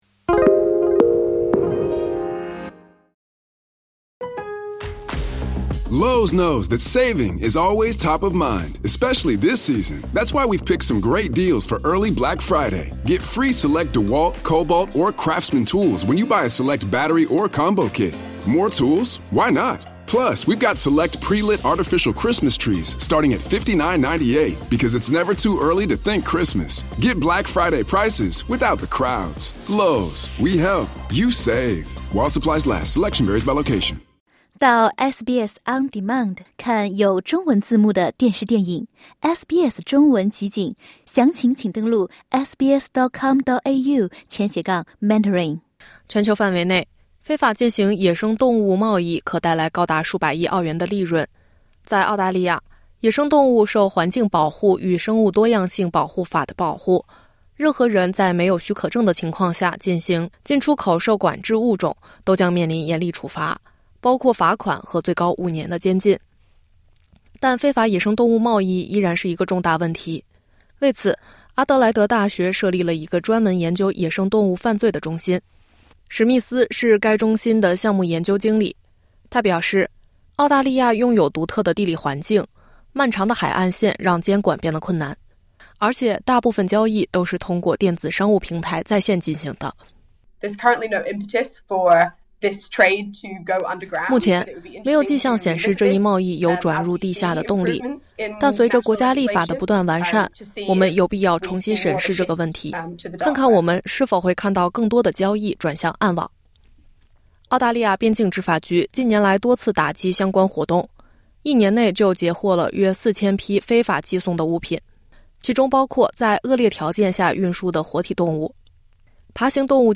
据估计，全球野生动物的非法贸易可带来高达320亿澳元的利润。澳大利亚因其物种珍稀独特，更容易成为黑市交易的目标（点击播客，收听采访）。